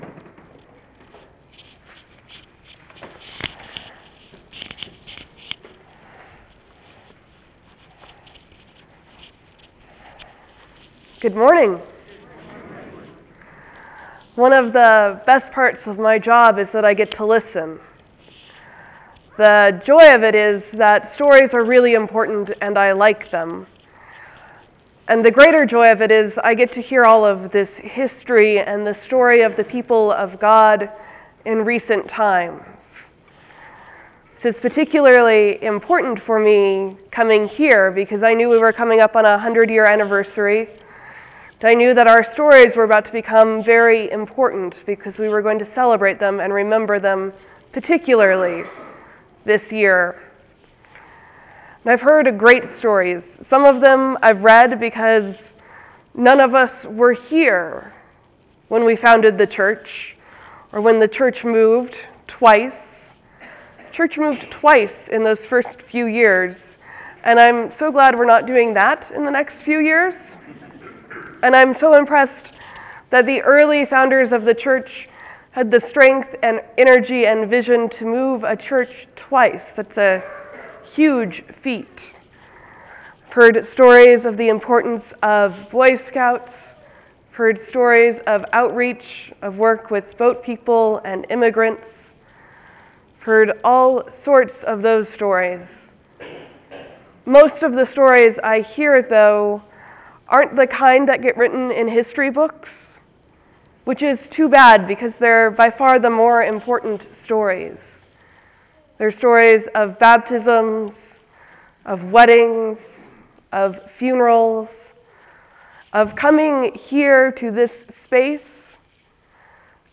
A Sermon for St Peter’s 100th Anniversary